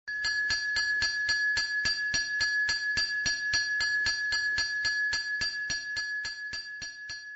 Tiếng gõ kẻng Lấy Rác, Đổ Rác Leng keng leng keng… (Mẫu số 2)
Thể loại: Tiếng chuông, còi
Description: Tiếng gõ kẻng Lấy Rác Leng keng leng keng... vang lên lanh lảnh, leng keng, loong coong, leng beng giữa phố phường tấp nập. Âm thanh kẻng đổ rác đặc trưng thường được dùng trong video miêu tả cảnh đô thị, khu dân cư, gợi cảm giác sinh hoạt quen thuộc, nhộn nhịp, gần gũi, gắn liền với hình ảnh người công nhân môi trường trong những buổi sáng sớm hoặc chiều muộn.
tieng-go-keng-lay-rac-leng-keng-leng-keng-mau-so-2-www_tiengdong_com.mp3